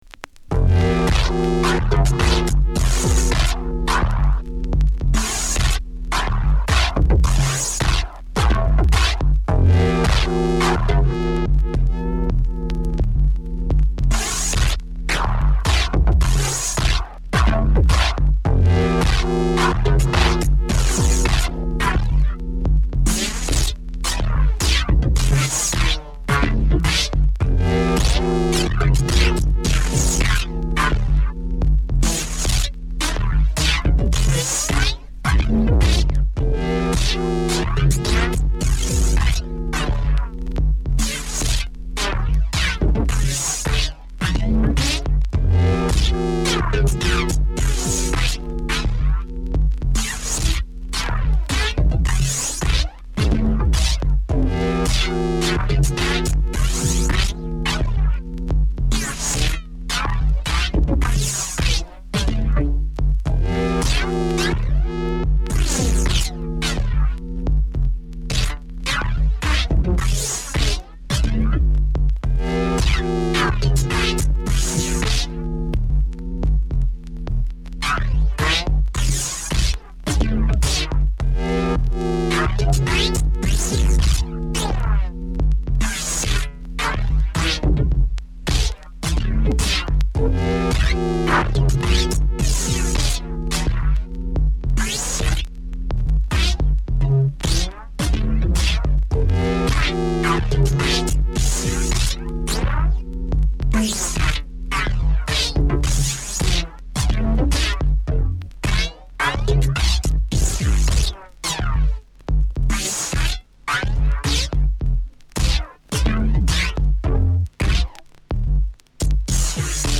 Techno , Abstract , Trip Hop